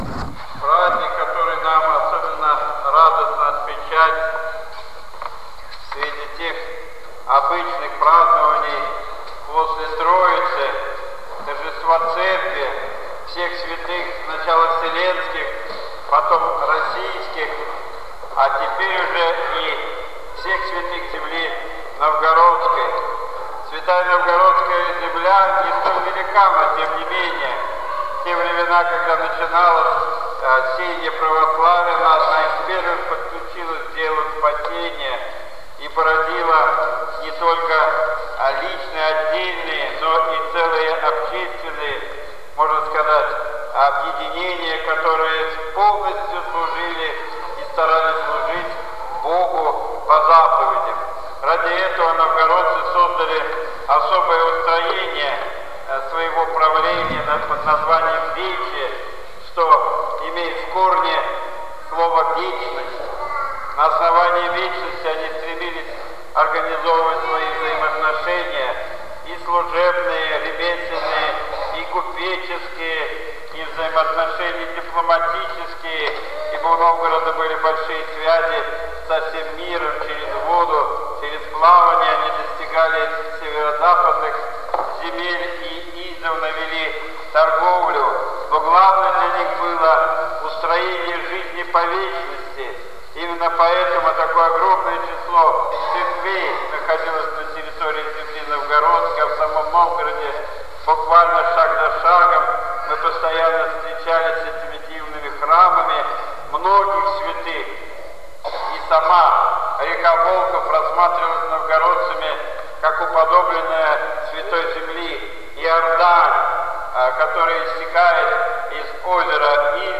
Слово
в День Святых Новгородской земли 24 июня 2012 г.